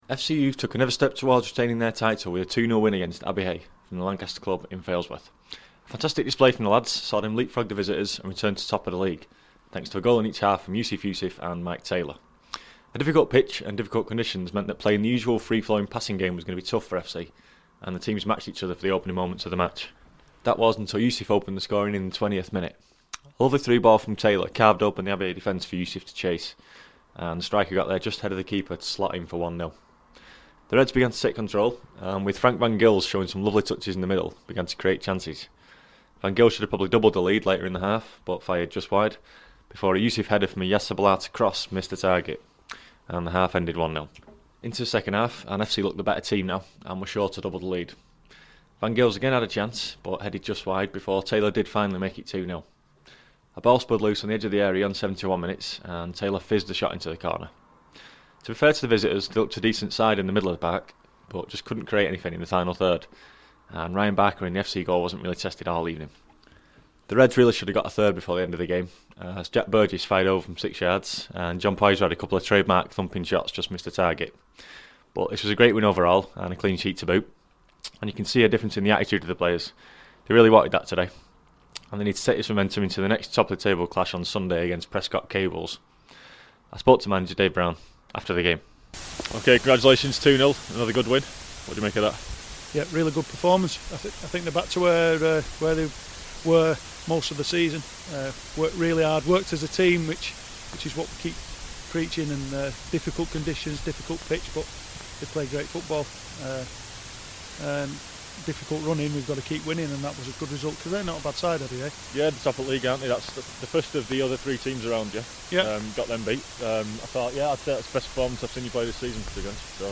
Youth Match Report - Abbey Hey (h)